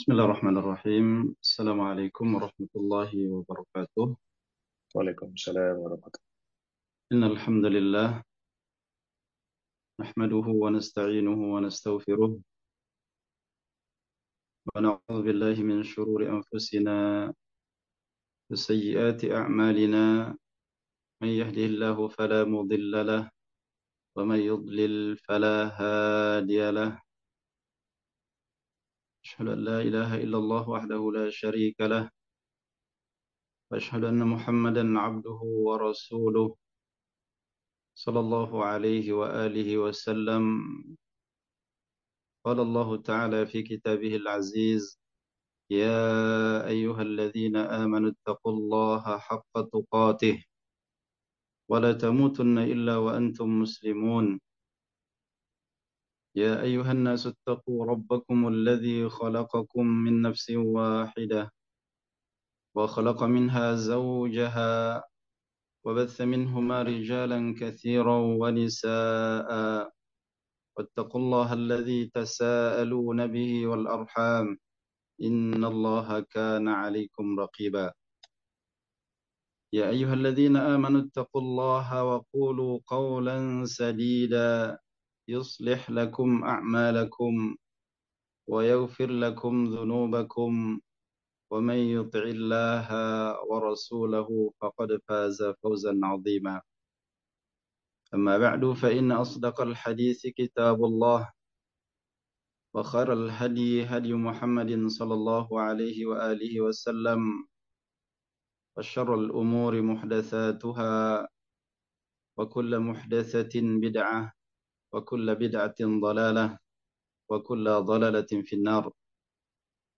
Kajian Online 3 – Daurah Ramadhan 1445H Doha